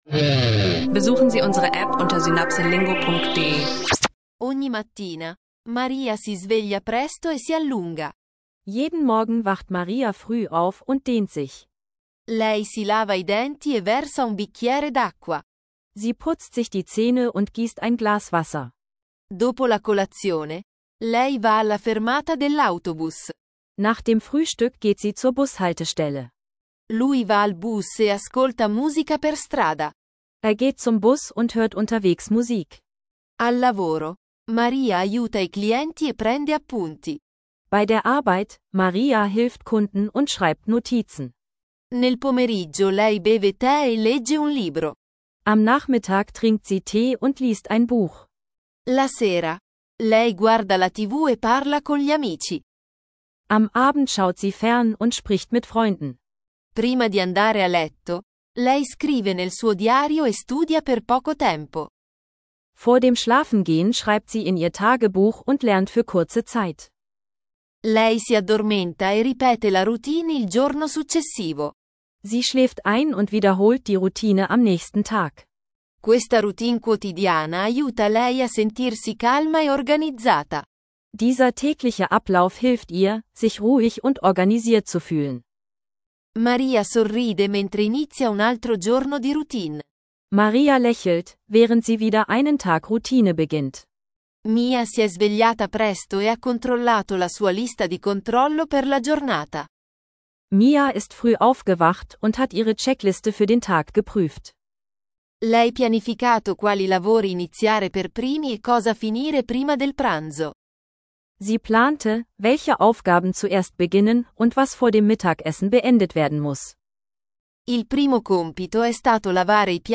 Lerne Italienisch durch alltägliche Routinen und Haushaltsaufgaben – praxisnaher Audio-Sprachkurs für Anfänger und Fortgeschrittene.